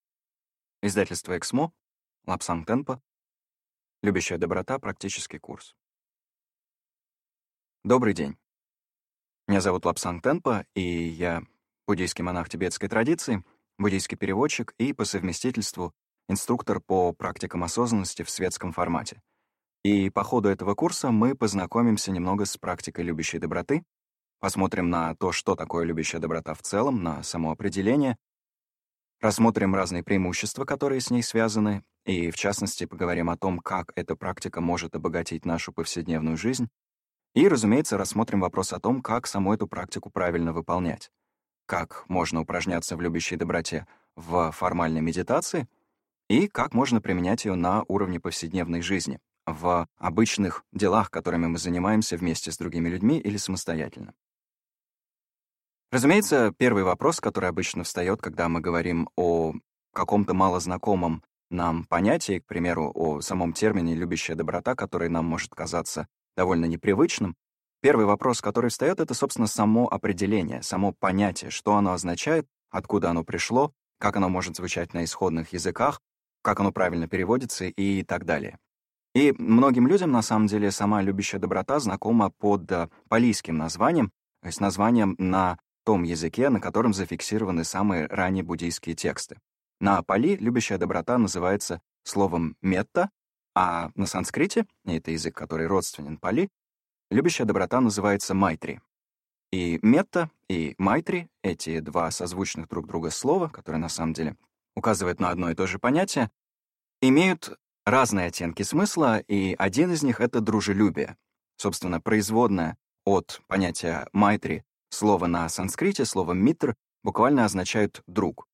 Аудиокнига Руководство по любящей доброте | Библиотека аудиокниг